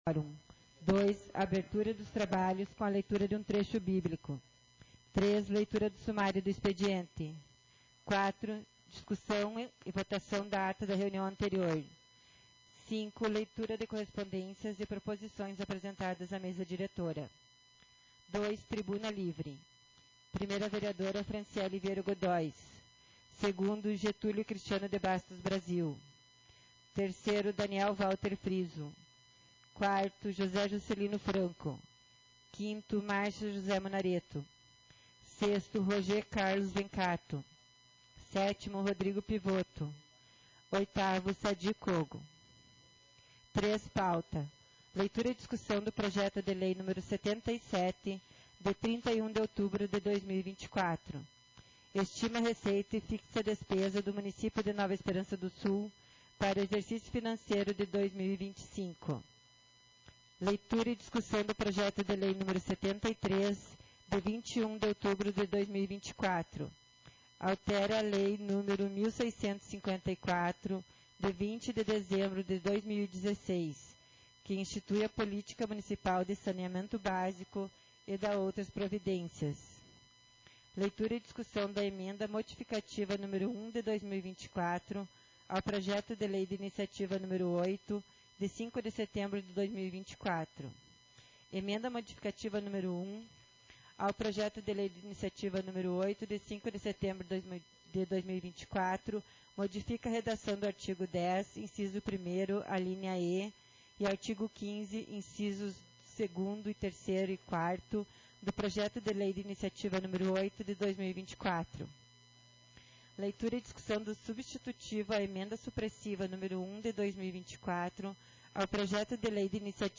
Sessão ordinaria 36-2024